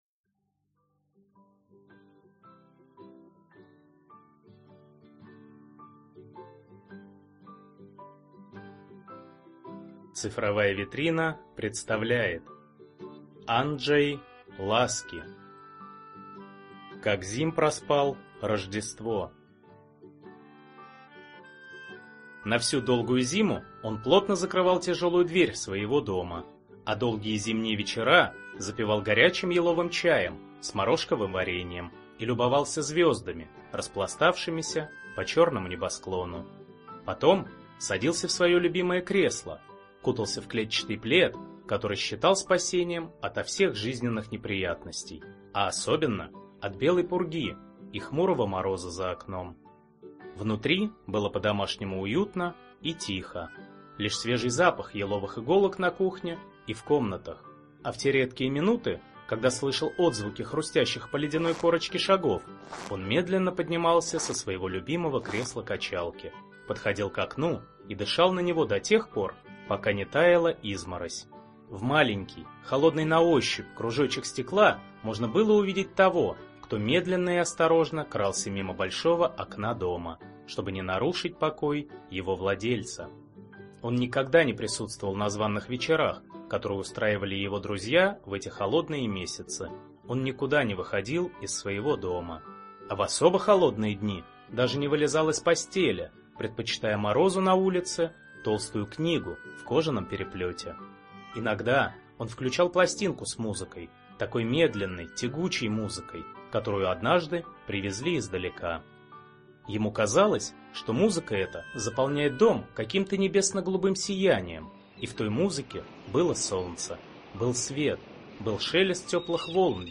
Аудиокнига Зимние истории | Библиотека аудиокниг